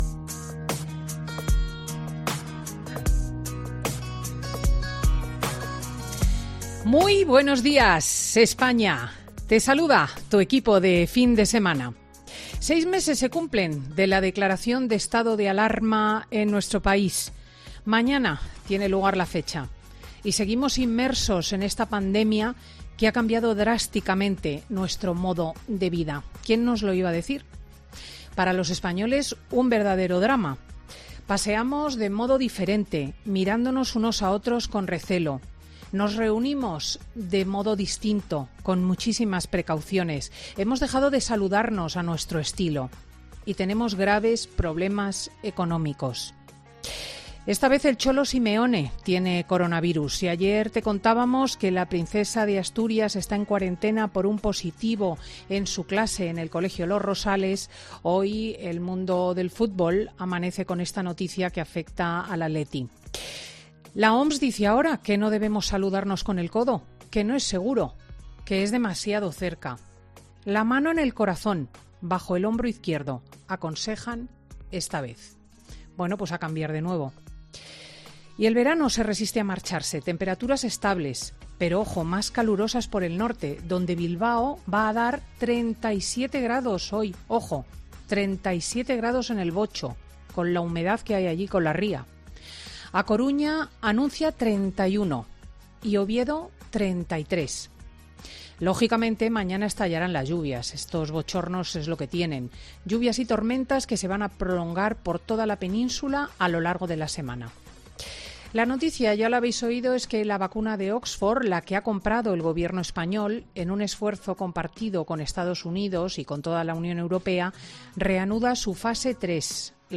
Monólogo de Cristina López Schlichting